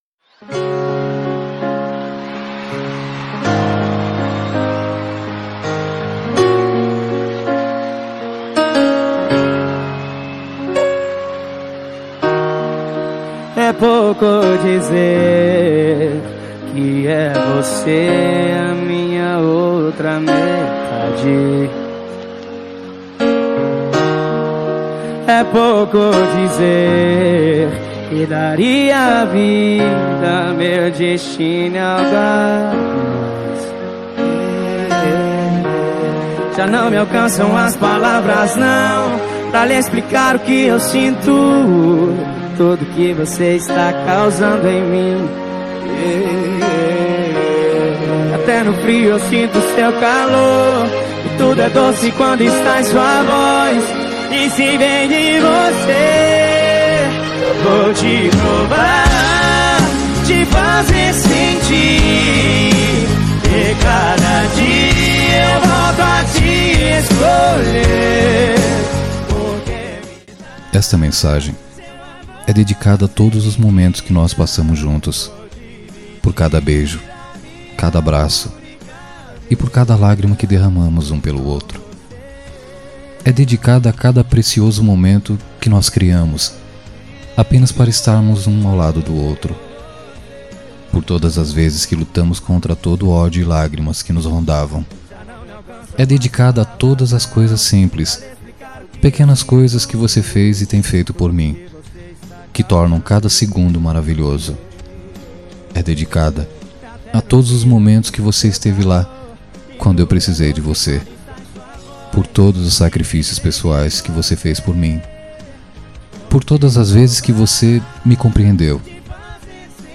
Telemensagem Romântica para Esposa – Voz Masculina – Cód: 9072